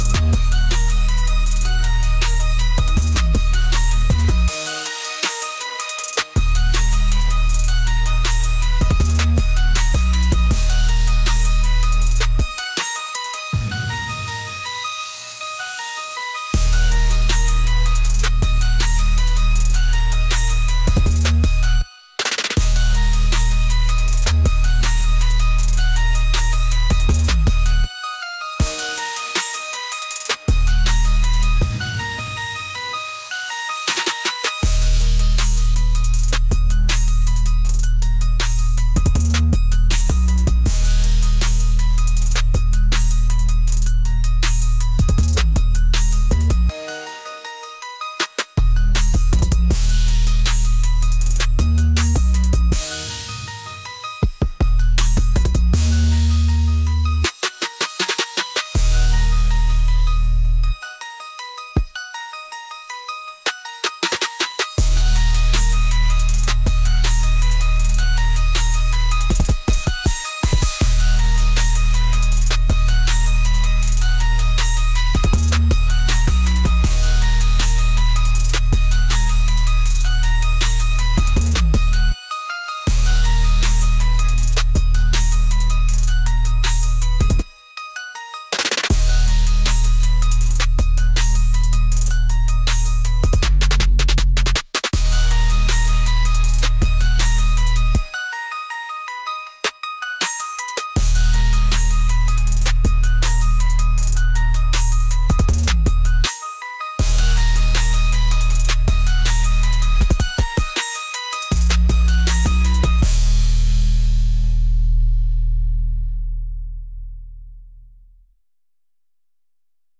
energetic | heavy